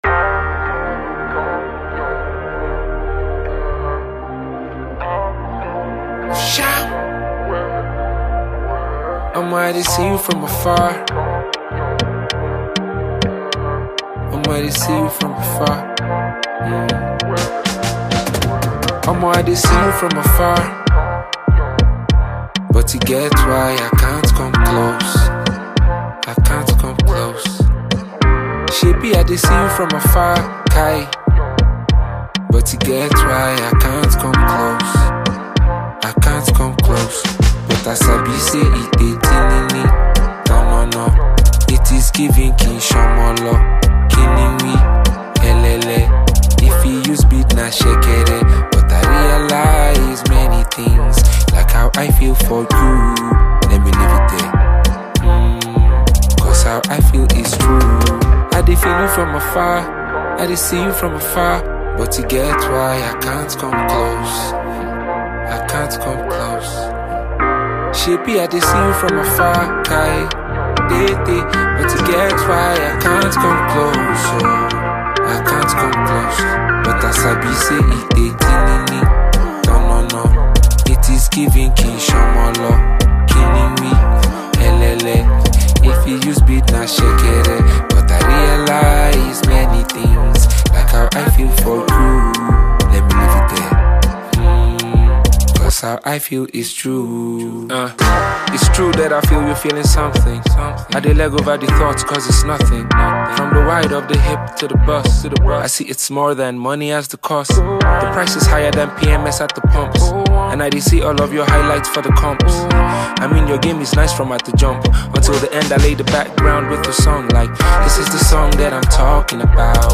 Talented Nigerian rapper and songwriter